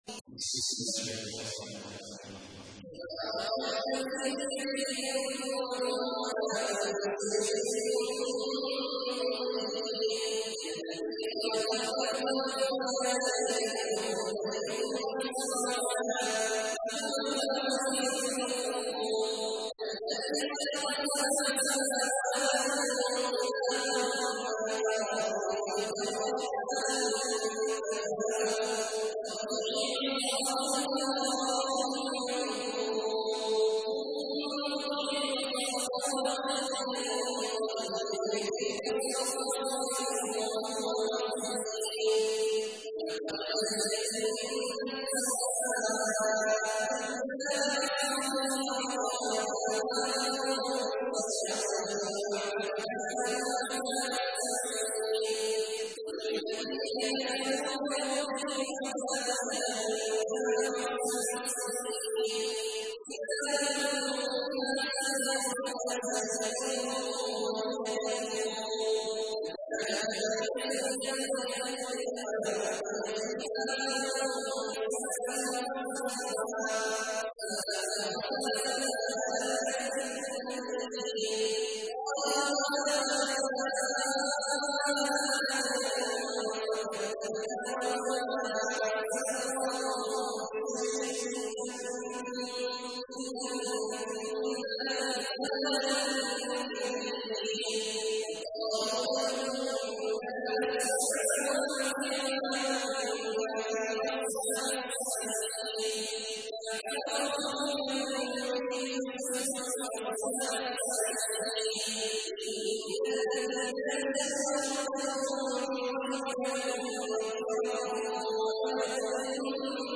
تحميل : 67. سورة الملك / القارئ عبد الله عواد الجهني / القرآن الكريم / موقع يا حسين